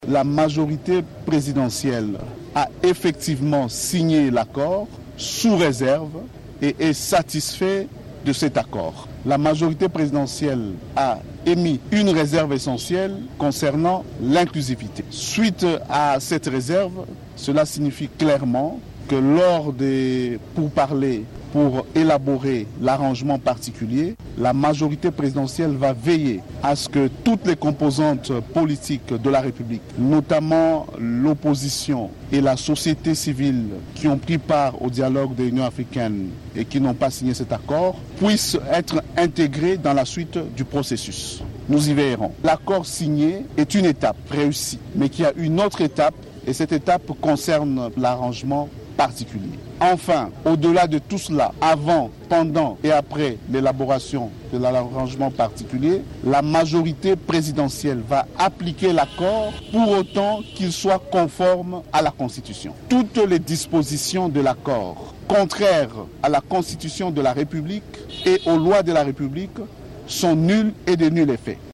Déclaration d’Aubin Minaku au micro de Top Congo FM